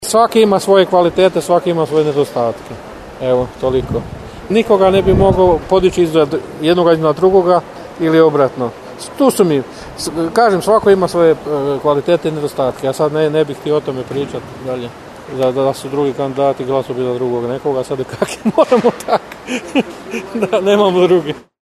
Anketa